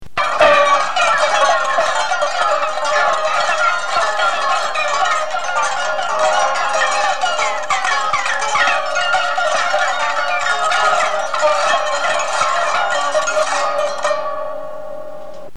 R.: ecco qua il nostro album sperimentale.